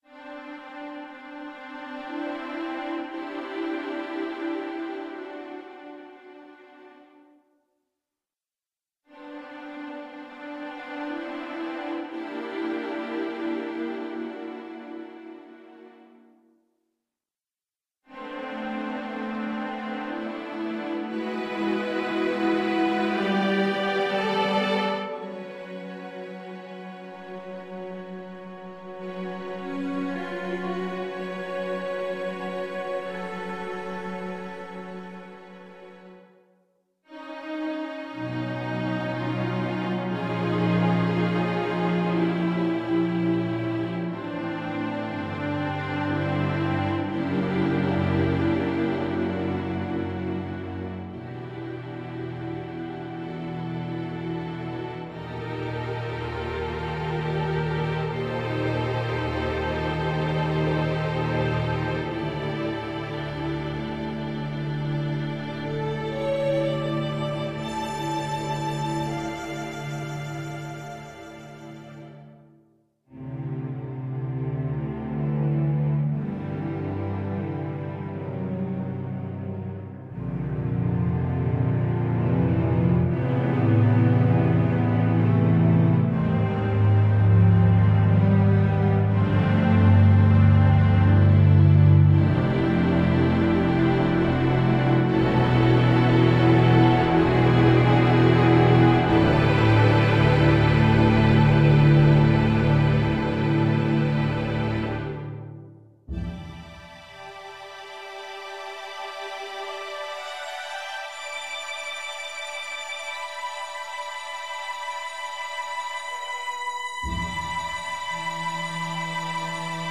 A new, evocative work commemorating the passing of an influential composer
Instrumentation:String Orchestra